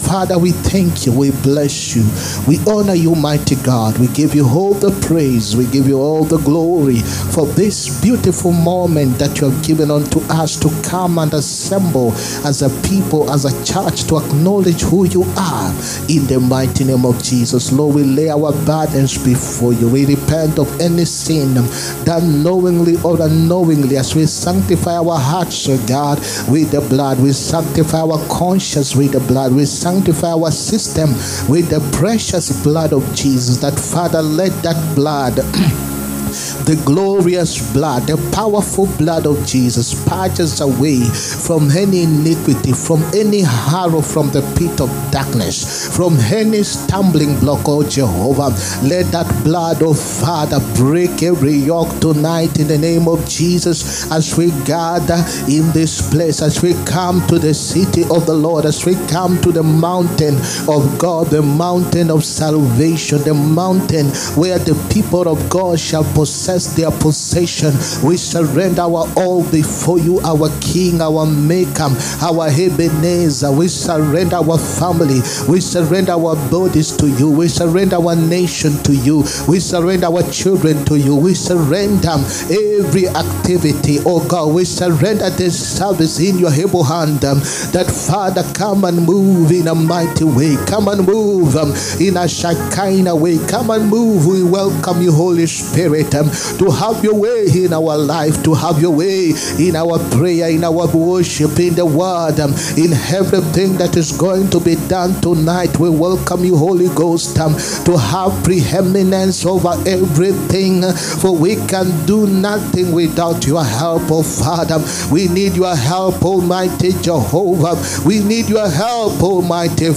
HEALING, PROPHETIC AND DELIVERANCE SERVICE. HOW TO APPROACH SPIRITUAL WARFARE. 15TH NOVEMBER 2024.